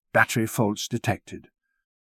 battery-faults-detected.wav